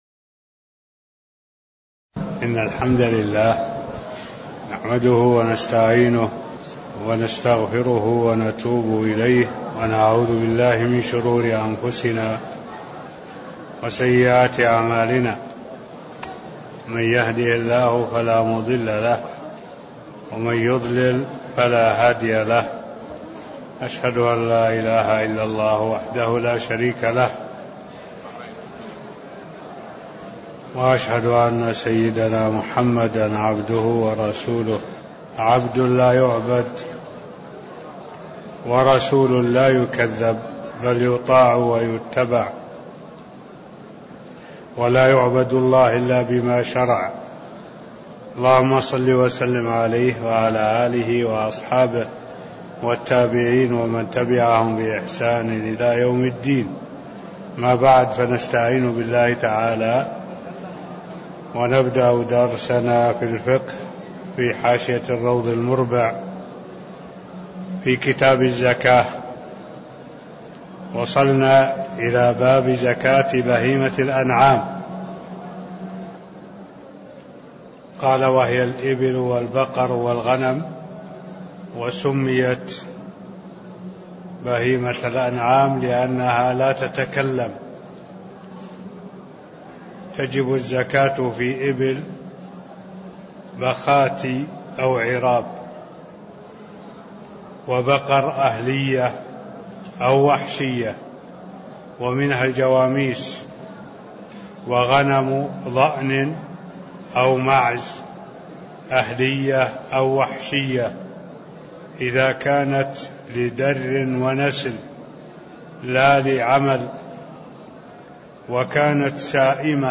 تاريخ النشر ٢٣ محرم ١٤٢٧ هـ المكان: المسجد النبوي الشيخ: معالي الشيخ الدكتور صالح بن عبد الله العبود معالي الشيخ الدكتور صالح بن عبد الله العبود فصل في زكاة الإبل (001)